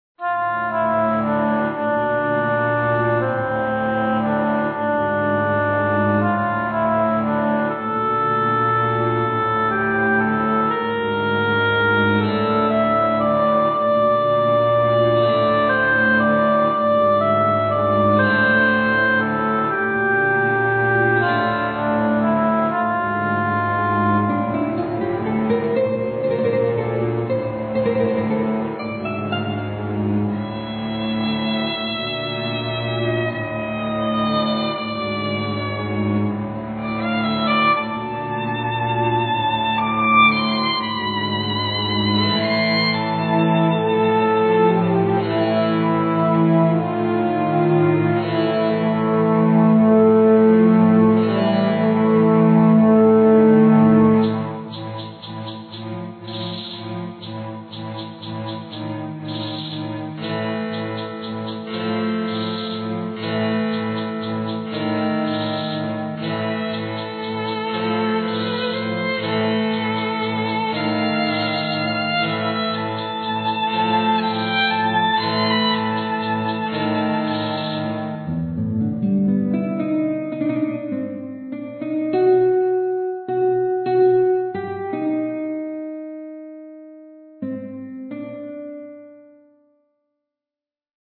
~ САУНДТРЕК ~